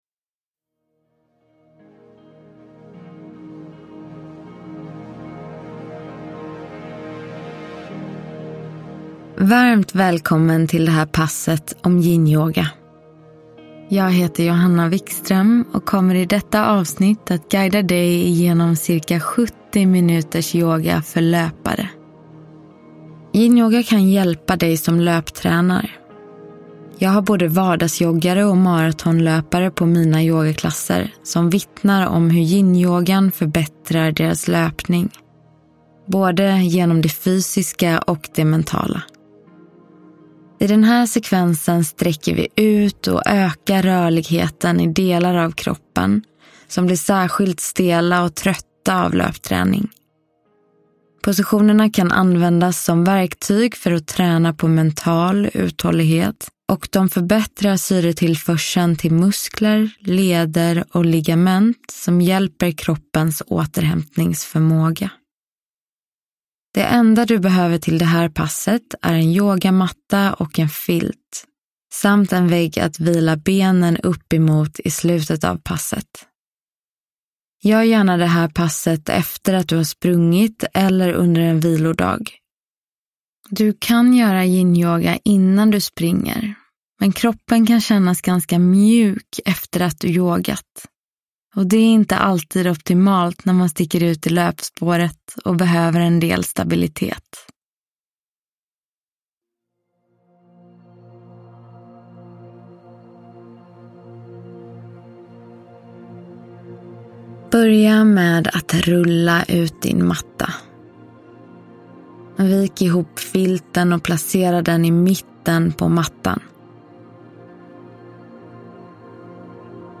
Yinyoga - Pass för löpare – Ljudbok
Träna hemma med kompletta pass för alla situationer det är bara att följa instruktionerna i ljudboken!